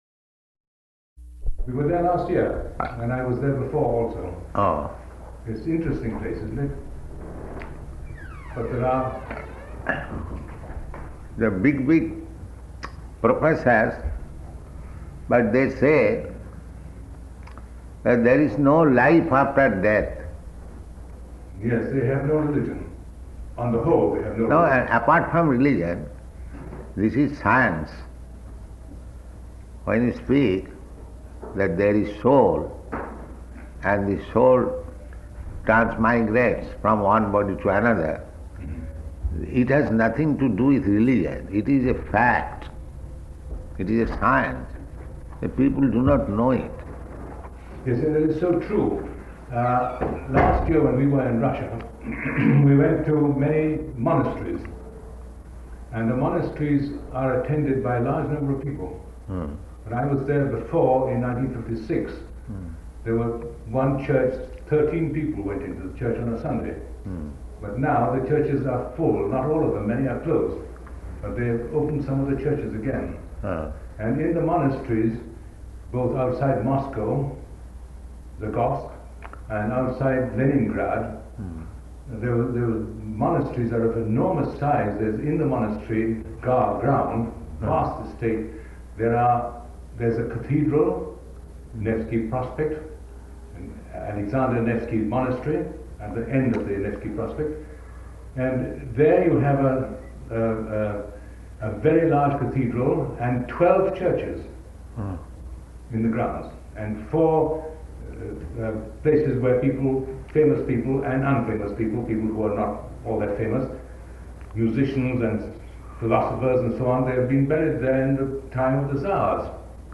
-- Type: Conversation Dated: July 23rd 1973 Location: London Audio file